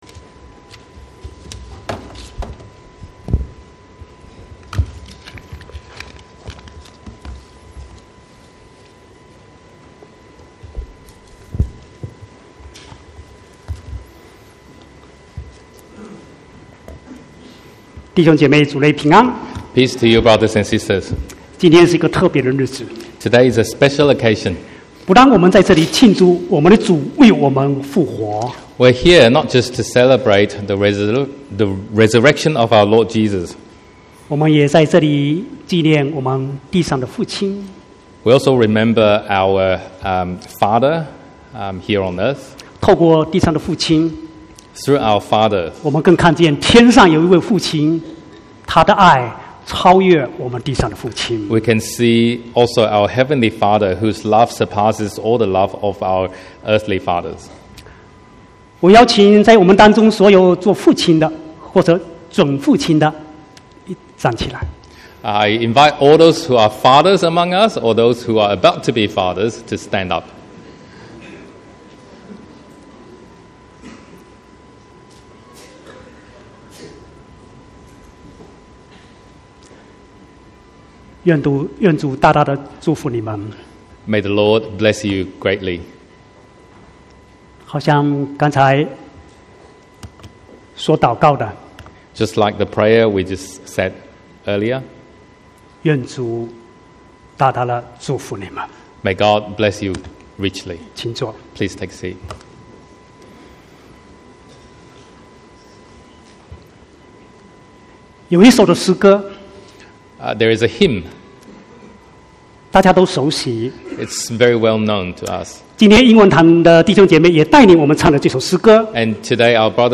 1/9/2019 國語堂講道